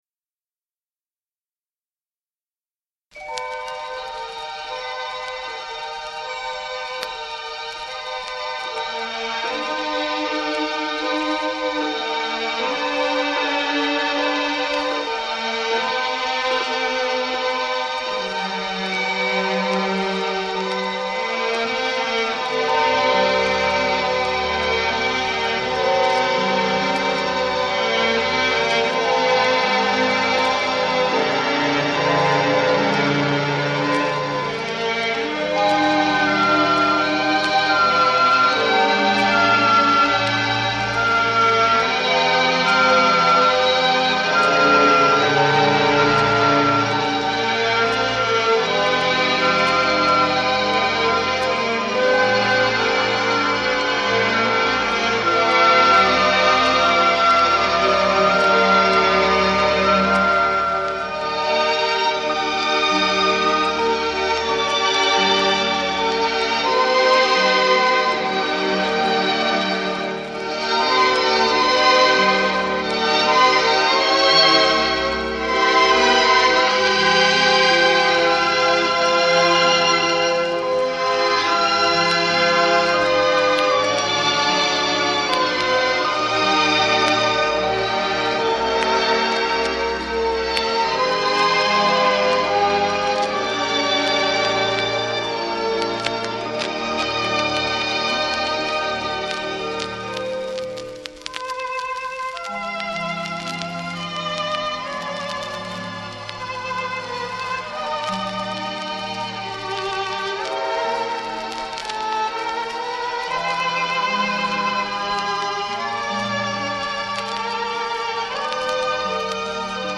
Music from the Soundtrack of